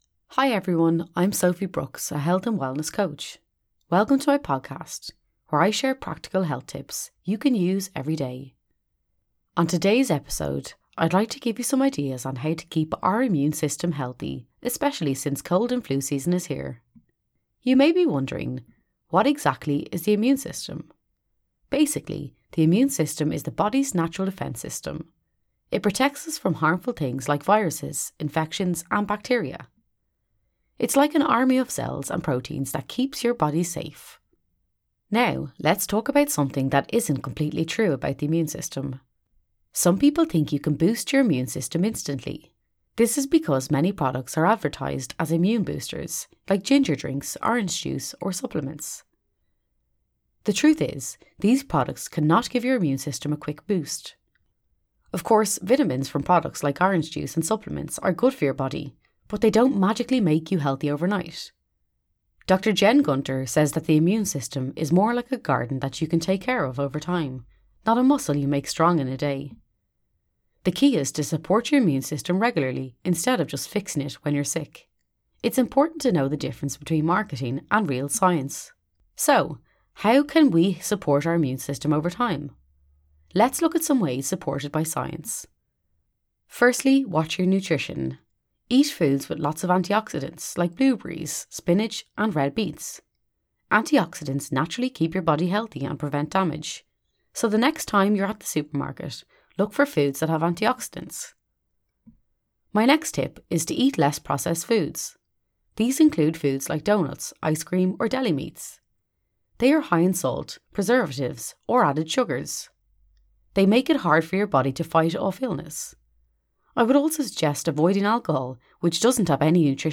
Students listen to a podcast episode by a health coach, who explains how to support the immune system during cold and flu season. The podcast covers the role of the immune system, common misconceptions about “immune boosters,” and practical strategies for maintaining immunity through nutrition, hydration, exercise, sleep, and stress management.